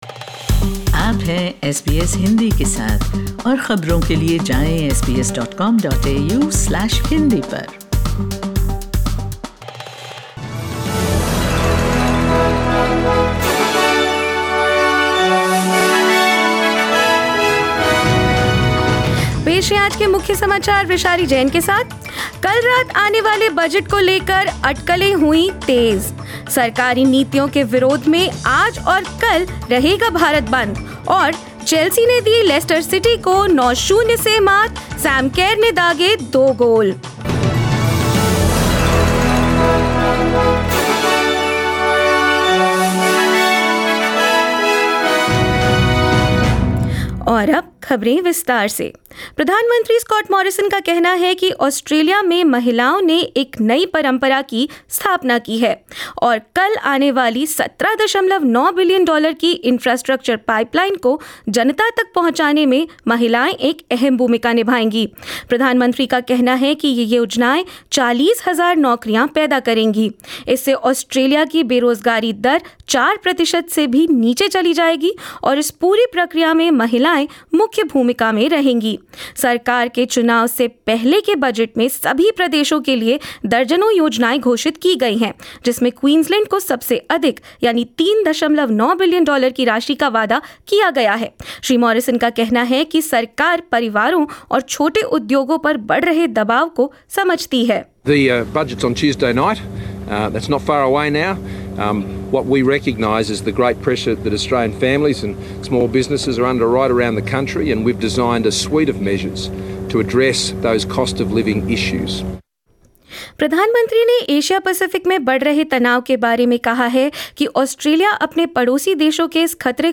SBS Hindi News 28 March 2022: Labor accuses Coalition of using last-minute spending to delay the election